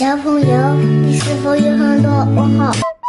SFX小朋友你是否有很多问号音效下载
SFX音效